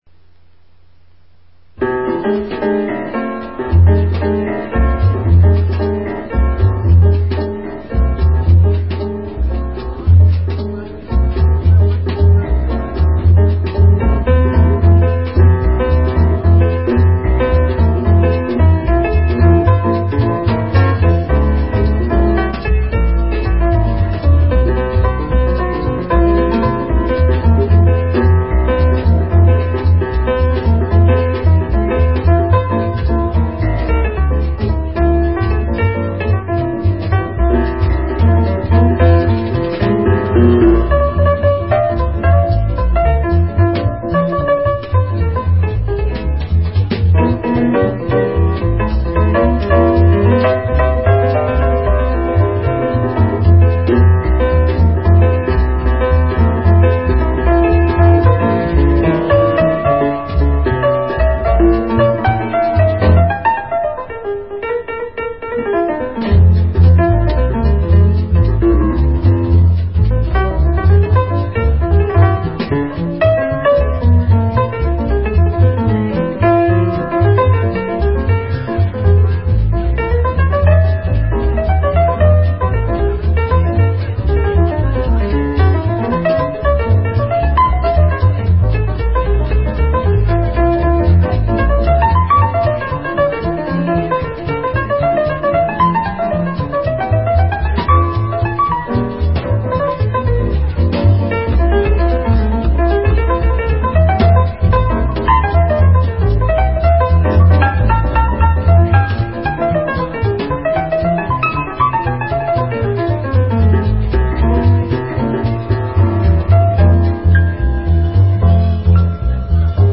piano
bass
drums
These selections are taken from a pre-production tape.